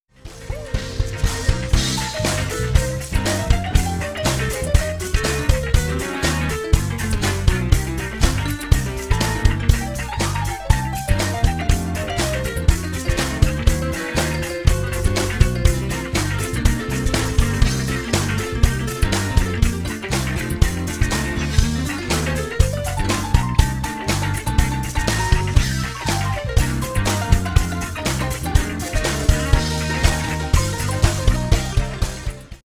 live im Sportcenter Stadium, Chengdu China